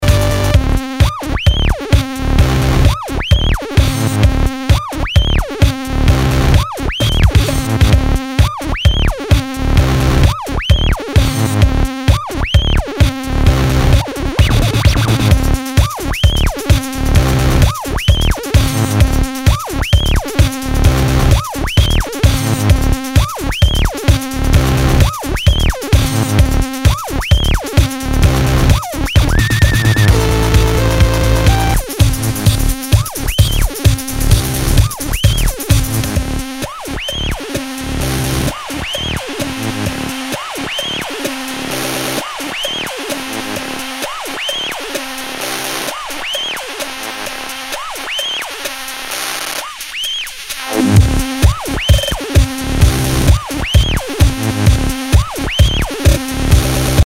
HOUSE/TECHNO/ELECTRO
ナイス！エレクトロ・ハウス！